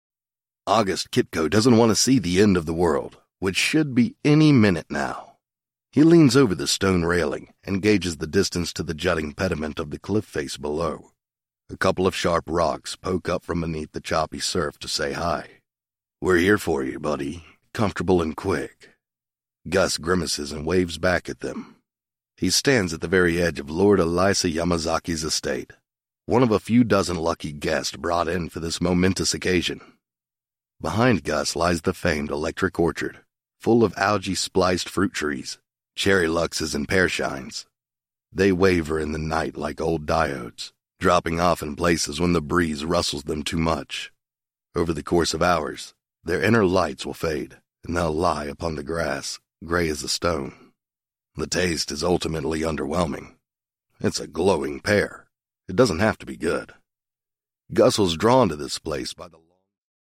sultry
İngilizce - Kuzey Amerika